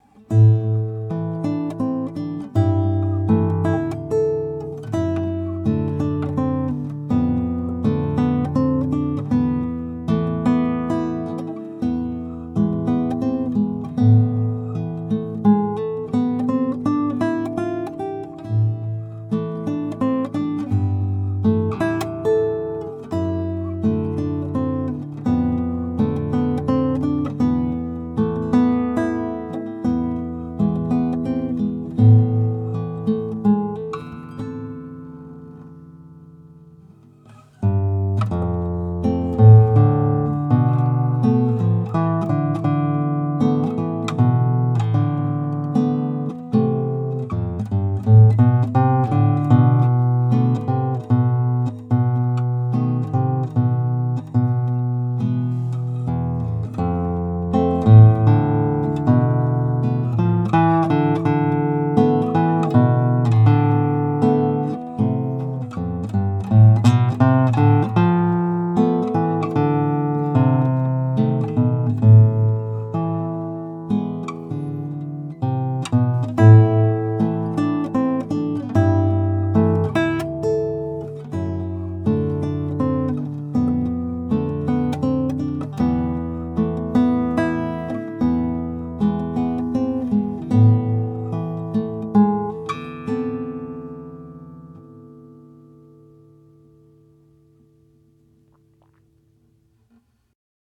Classical guitar recordings